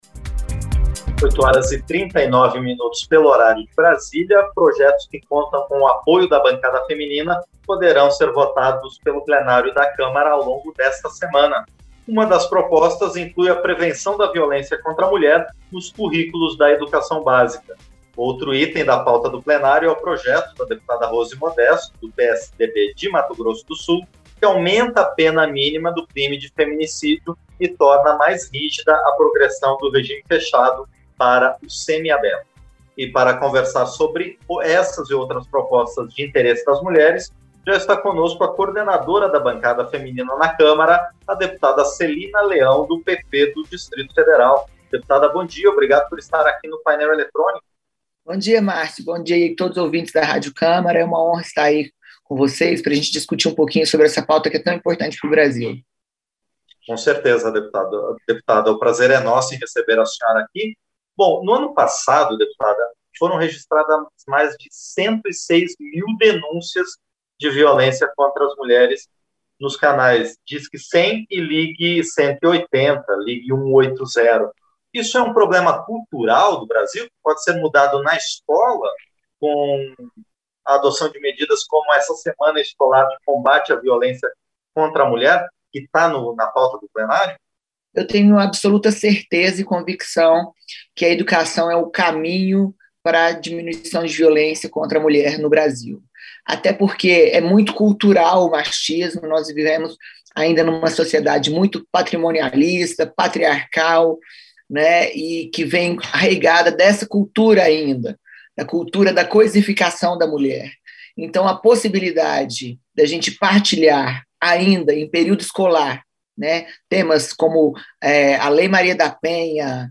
Entrevista - Dep. Celina Leão (PP-DF)